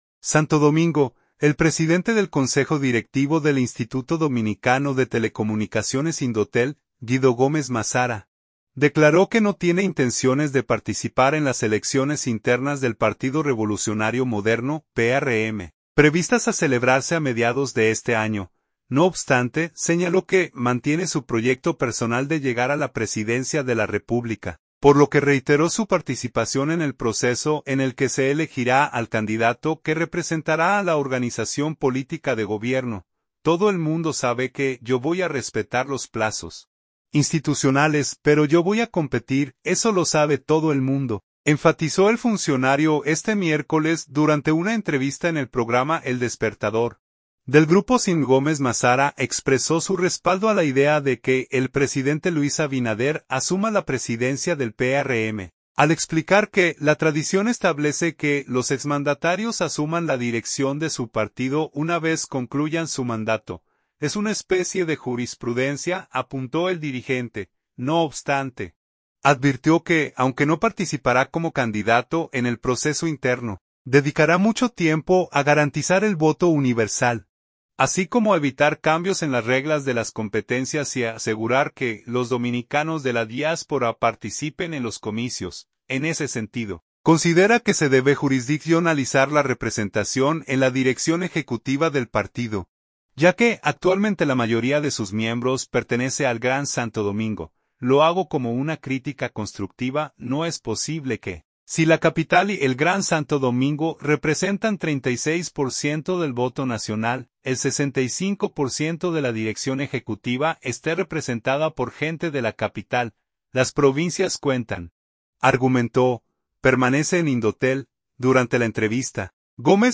“Todo el mundo sabe que yo voy a respetar los plazos institucionales, pero yo voy a competir; eso lo sabe todo el mundo”, enfatizó el funcionario este miércoles, durante una entrevista en el programa El Despertador, del Grupo SIN.